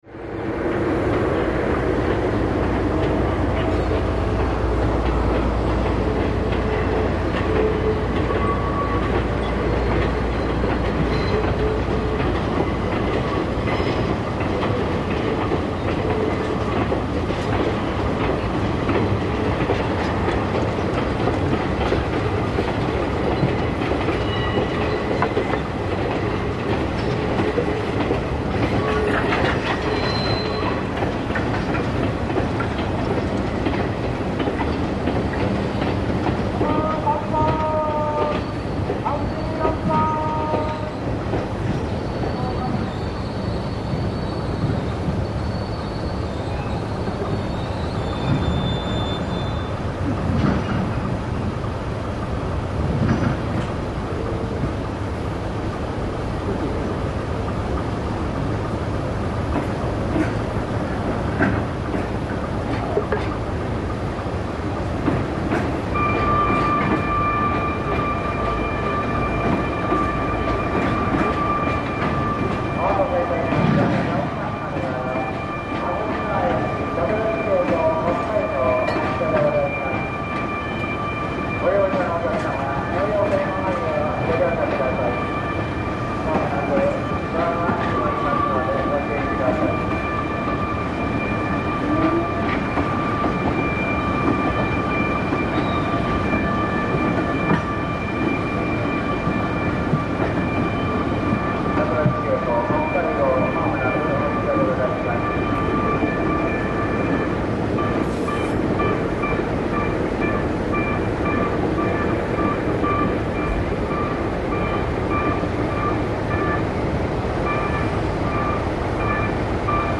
ＤＤ13が入れ替えをしている早朝の函館駅。特急「北海」の発車ベルが鳴り出しますが、なかなか列車は発車しません。
「北海」と並んで止まっている後発のニセコ1号の窓からの録音。録音中ずっと聞こえているアイドリングはニセコのキハ56の音。